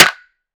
SNARE.68.NEPT.wav